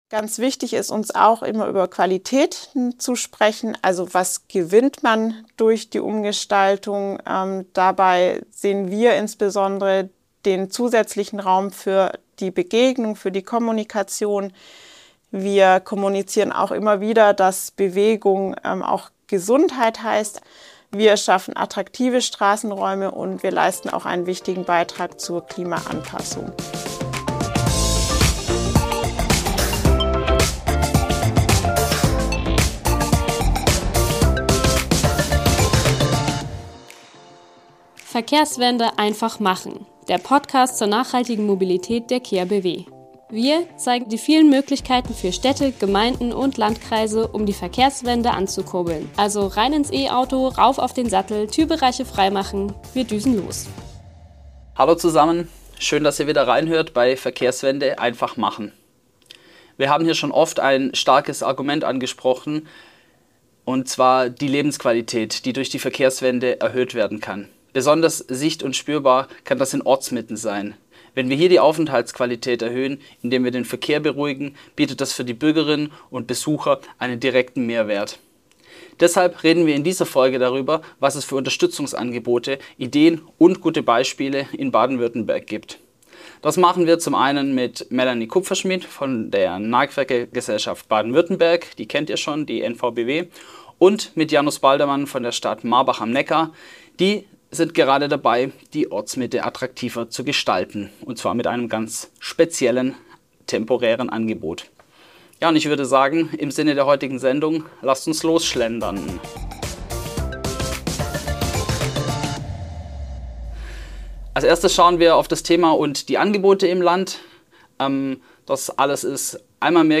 in Marbach am Neckar auf temporären Sitzmöglichkeiten mitten in der Stadt unterhalten